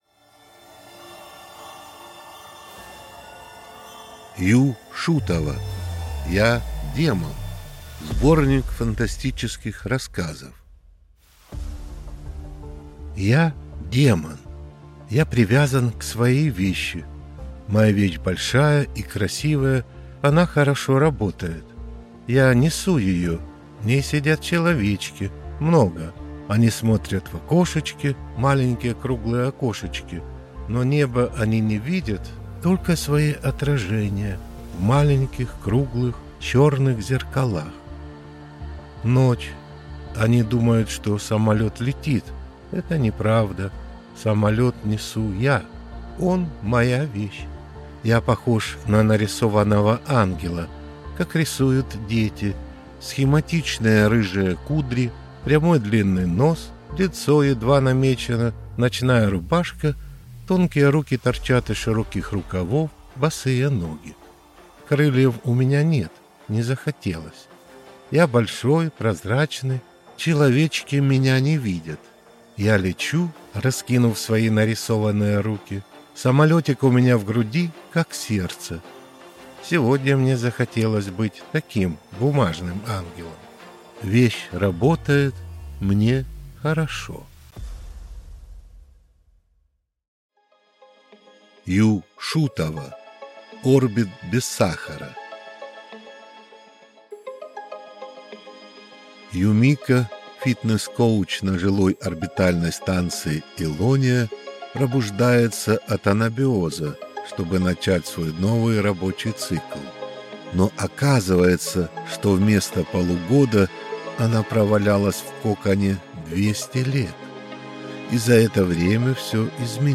Аудиокнига Я – Демон | Библиотека аудиокниг